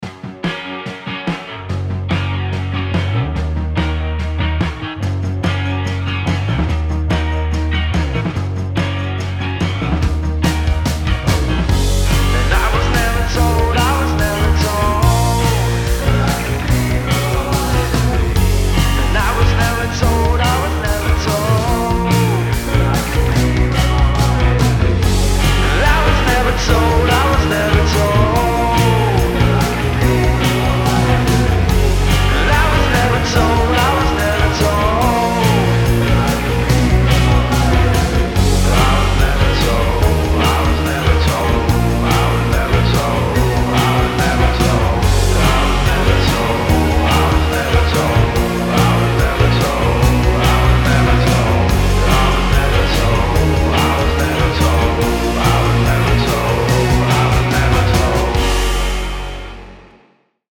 Super-fuzzed hazy guitar effervescence